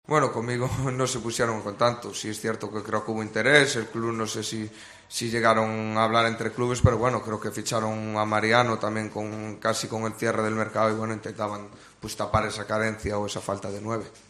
"En el fútbol mandan los resultados. Es cierto que tuvieron muchos cambios y la marcha de Cristiano, que a veces tapaba muchas carencias con sus goles a pesar de que no hacían un buen partido. A Julen no le dio tiempo a imponer su método para ir a más", comentó el delantero del Celta de Vigo en rueda de prensa.